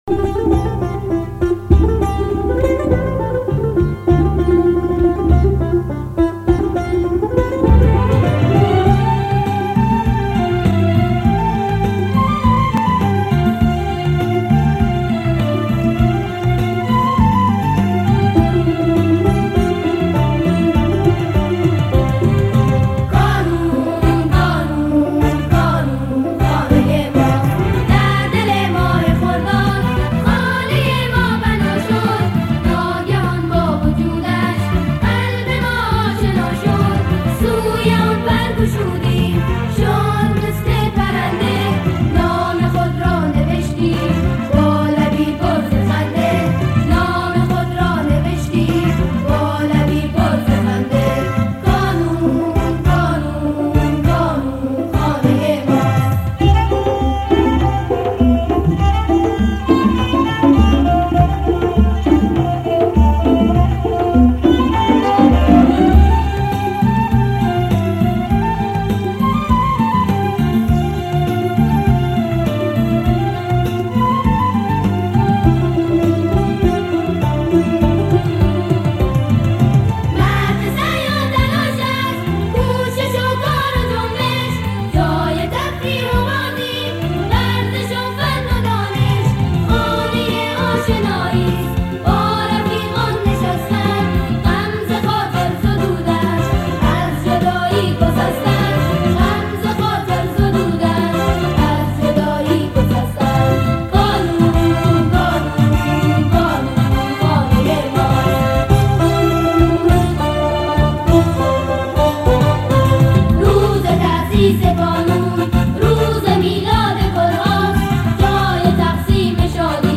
سرود کانون خانه ما